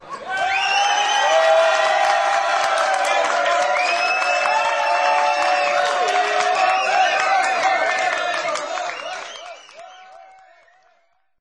APLAUSOS Y GRITOS
Tonos EFECTO DE SONIDO DE AMBIENTE de APLAUSOS Y GRITOS
Aplausos_y_gritos.mp3